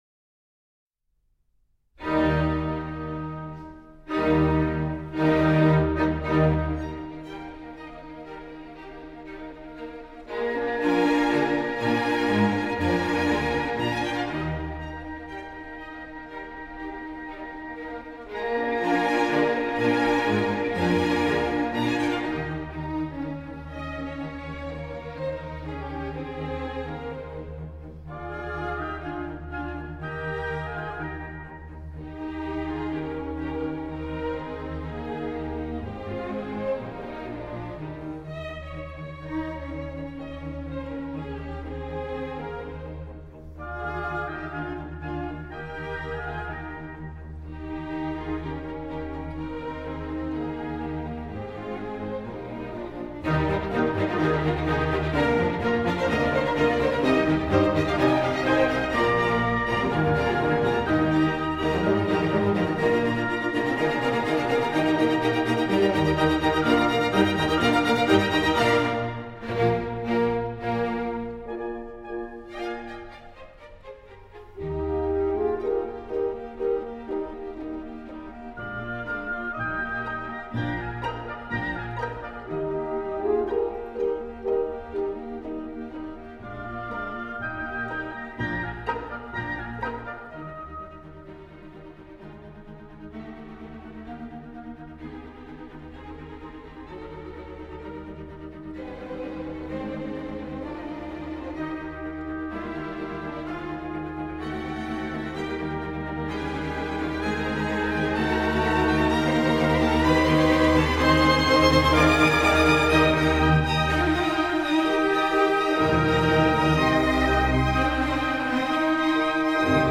Group: Orchestra